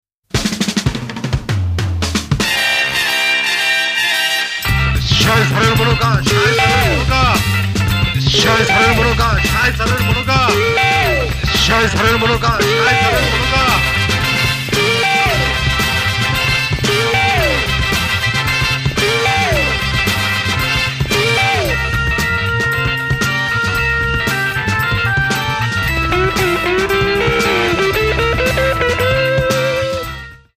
drums
trumpet/flugelhorn
elec.guitar/fork guitar/sitar